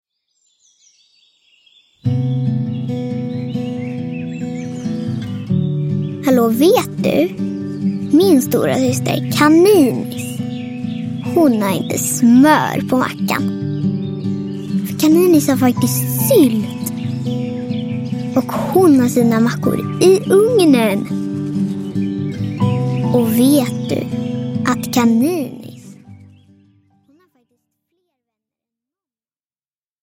Syskonvecka – Ljudbok – Laddas ner